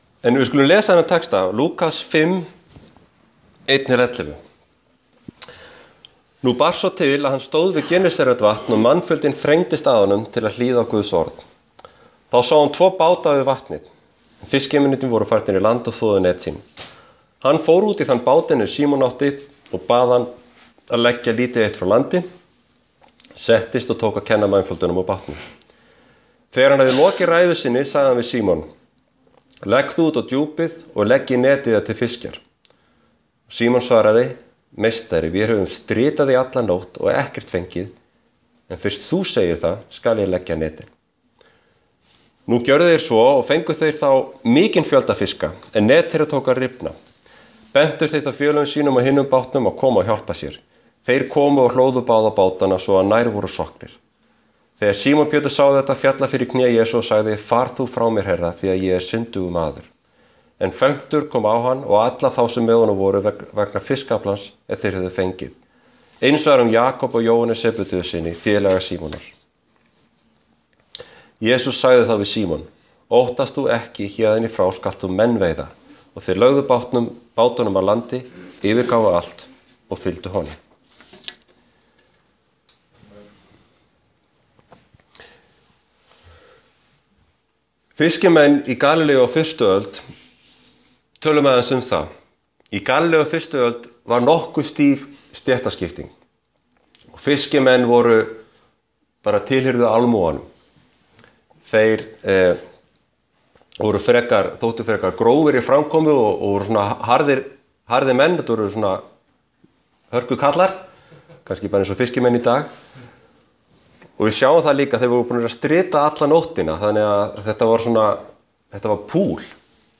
Hvað breytist þegar það er Jesús sem biður okkur?Predikað í Hvítasunnukirkjunni á Selfossi 15.sept 2013.